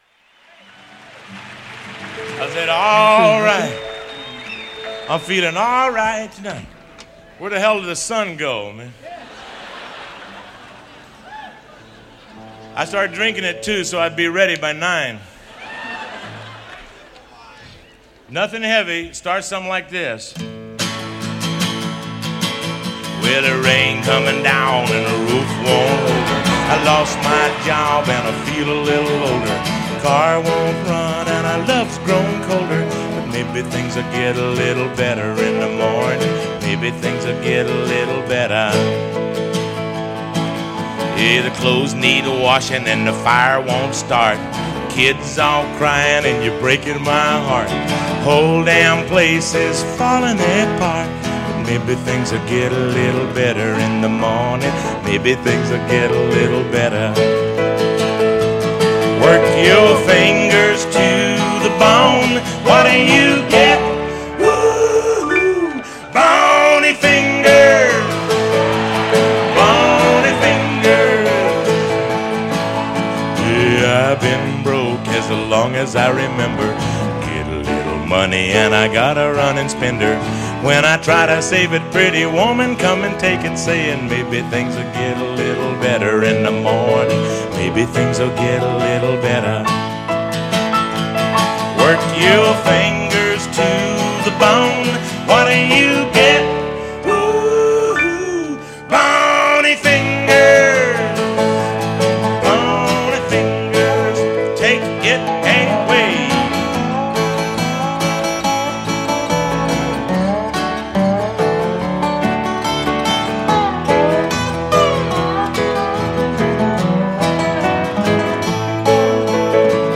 His own live rendition
folk festival